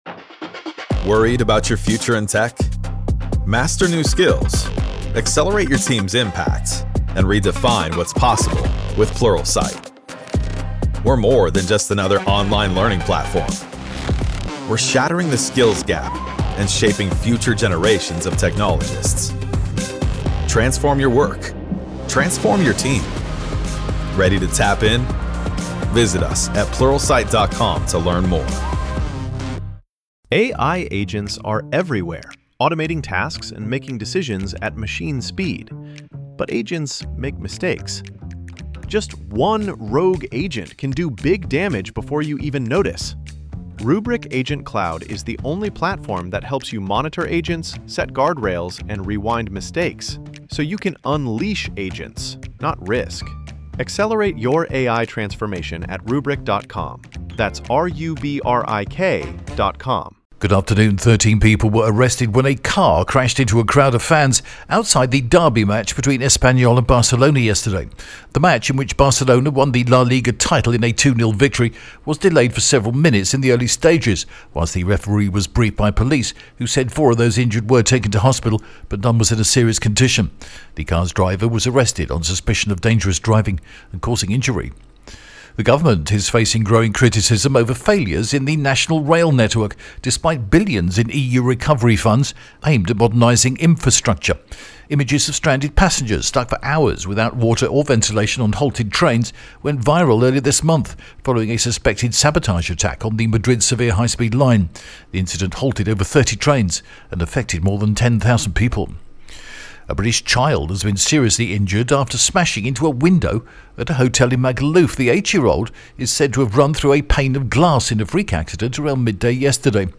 TRE is the only broadcaster in Spain to produce and broadcast, twice daily its own, in house, Spanish and local news service in English...and we offer this to you as a resource right here, and on the hour at tre.radi...